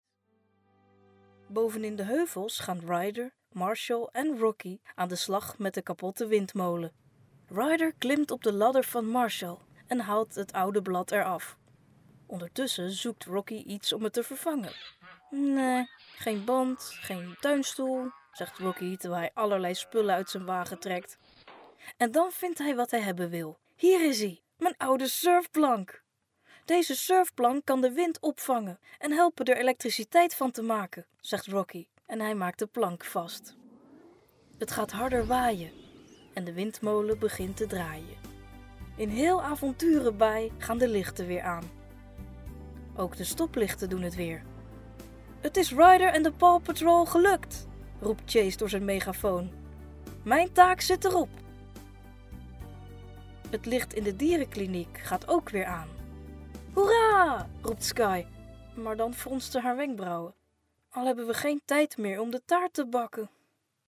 Ingelezen tekst uit een voorleesboek voor jonge kinderen:
Ingelezen-tekst-uit-een-voorleesboek-voor-jonge-kinderen-1.mp3